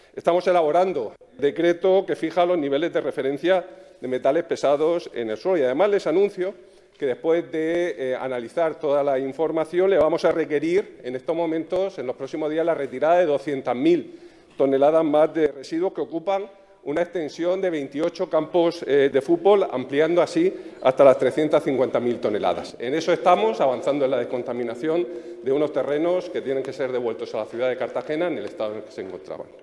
El consejero de Medio Ambiente, Universidades, Investigación y Mar Menor, Juan María Vázquez, en la Asamblea habla sobre el requerimiento que se le va a hacer a Zinsa en los próximos días.